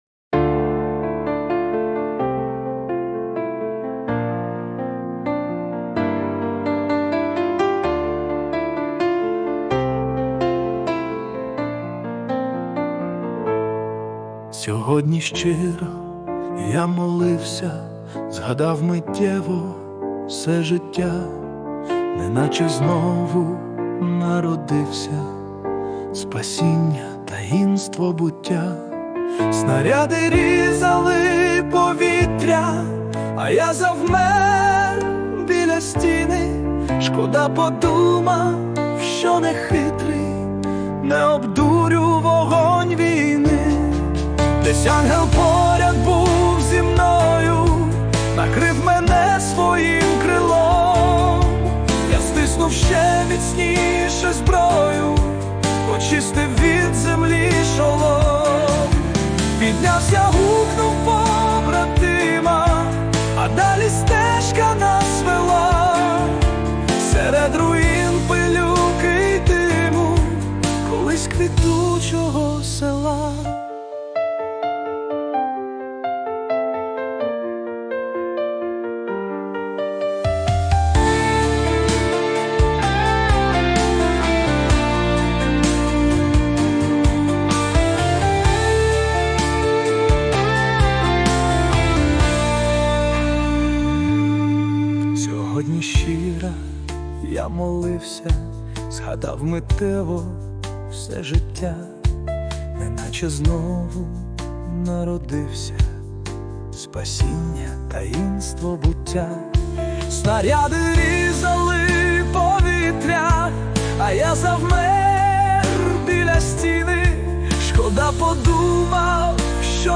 Слова - автора, музика - ШІ